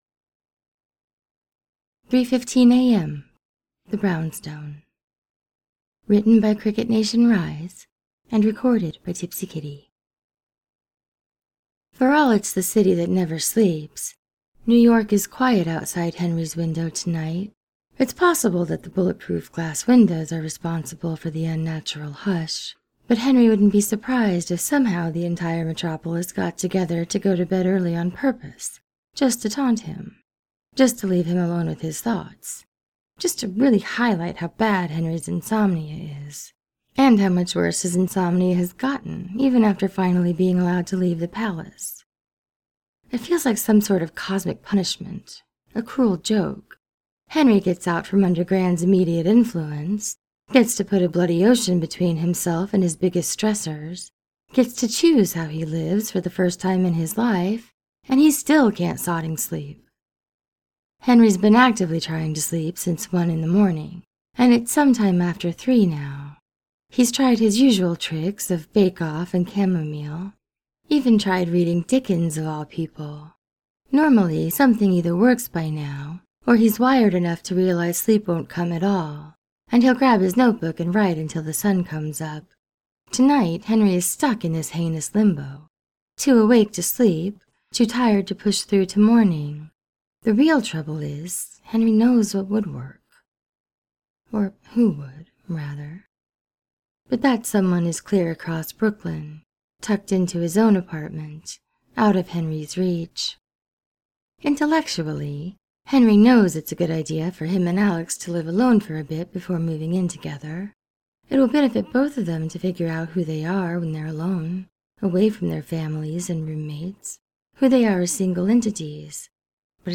without music: